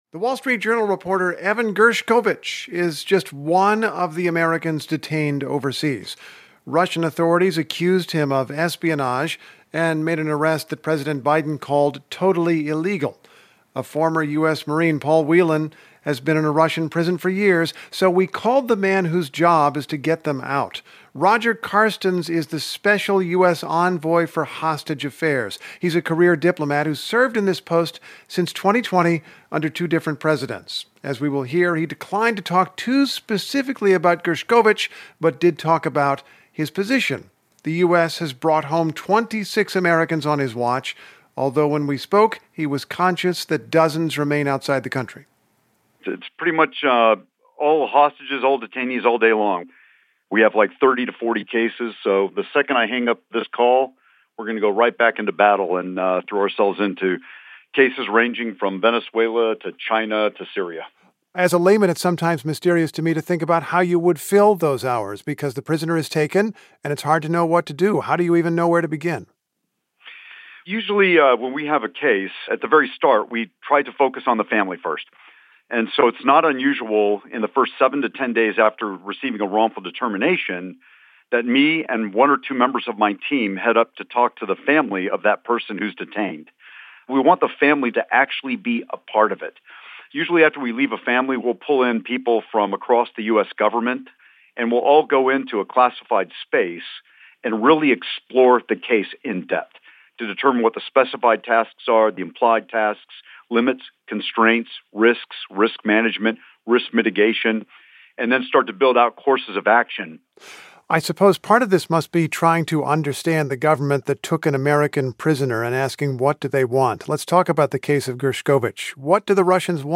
"We will bring Paul Whelan and Evan Gershkovich home," Carstens tells NPR's Steve Inskeep on Morning Edition.
This interview has been edited for length and clarity.